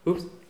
oups_04.wav